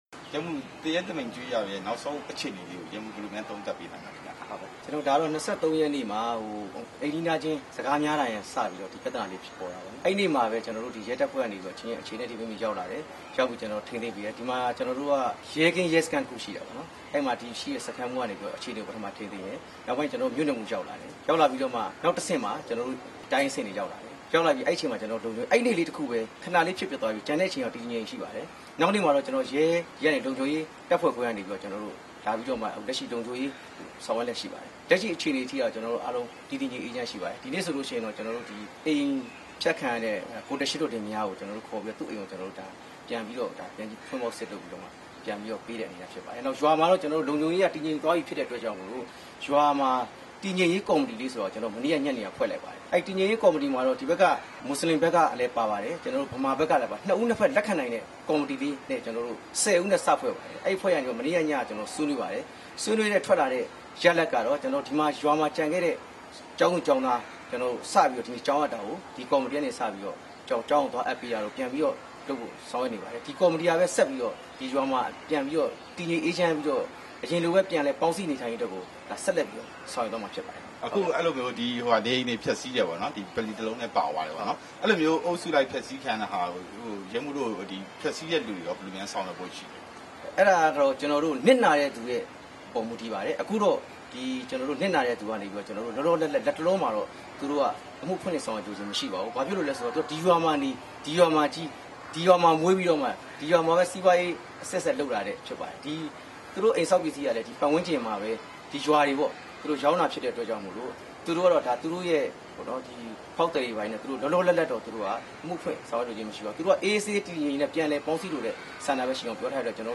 ပဲခူးတိုင်းဒေသကြီး ရဲတပ်ဖွဲ့မှူး ရဲမှူးကြီး မျိုးမင်းထိုက် နဲ့ မေးမြန်းချက်